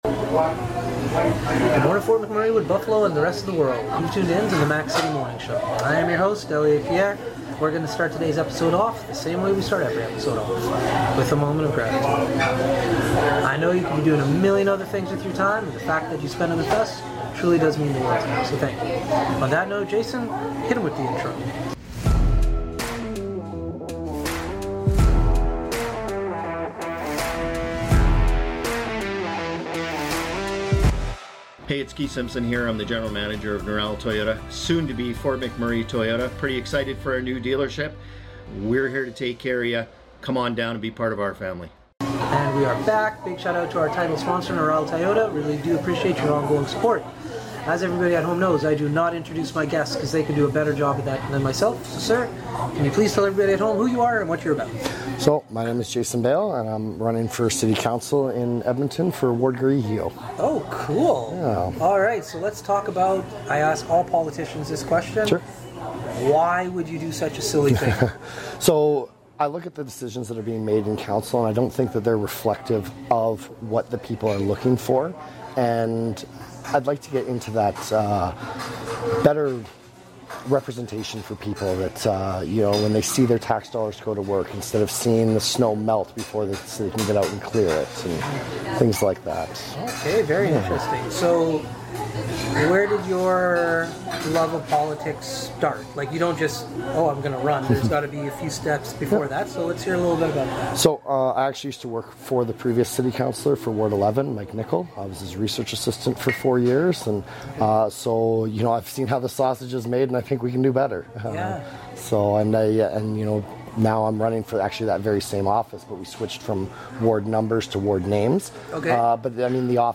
We are back on location in Edmonton at Cask and Barrel